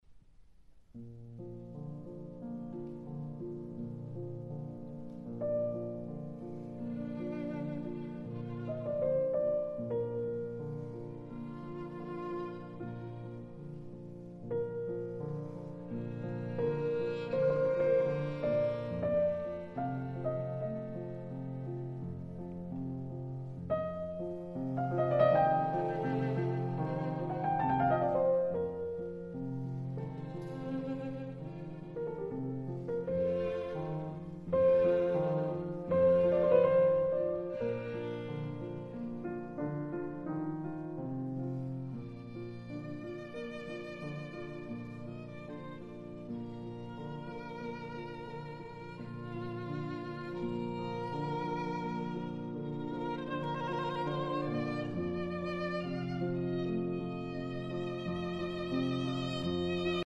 Ludwig van Beethoven - Violin Sonata No.5 In F Major, Op.24 'Spring' - 2. Adagio